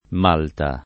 vai all'elenco alfabetico delle voci ingrandisci il carattere 100% rimpicciolisci il carattere stampa invia tramite posta elettronica codividi su Facebook Malta [ m # lta ; malt. m # lta ; ingl. m 0 o N të ] top. — l’isola mediterranea — cfr.